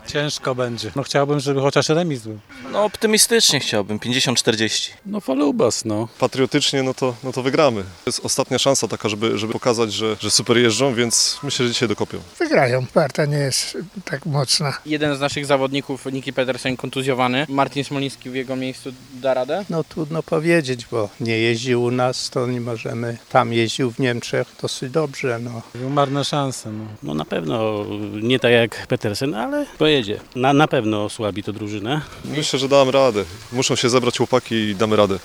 Zapytaliśmy zielonogórzan, czy liczą na zwycięstwo żużlowców z Winnego Grodu?: